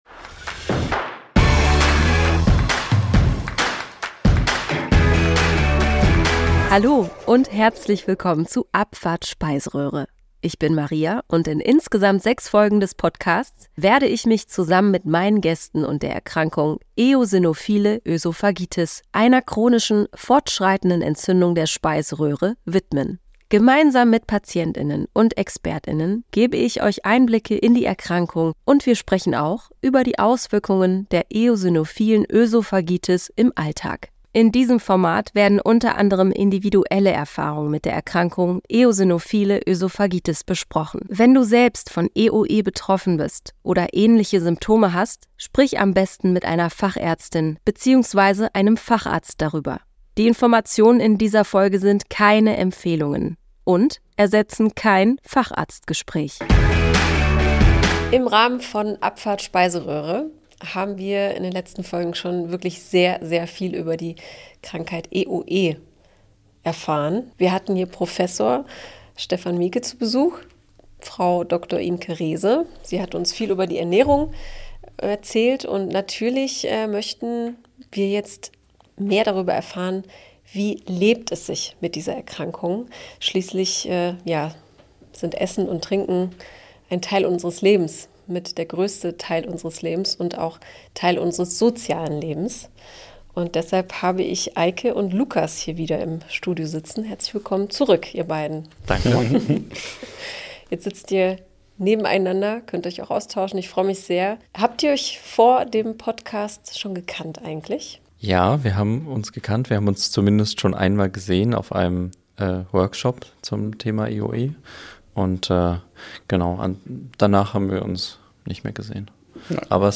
In der vorerst letzten Folge plaudern die beiden EoE Betroffenen aus dem Nähkästchen. Dabei interessiert uns besonders, wie die beiden mit der Erkrankung im Alltag umgehen, wie ihr Umfeld vor und nach der Diagnose auf die Beschwerden und Lebensumstellungen reagierte und was die beiden sich für die Zukunft wünschen.